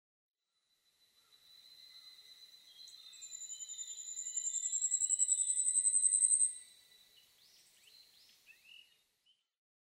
ヤブサメ　Urosphena squameicepusウグイス科
日光市稲荷川中流　alt=730m  HiFi --------------
Rec.: MARANTZ PMD670
Mic.: audio-technica AT825
他の自然音：　 ヒグラシ・ハシブトガラス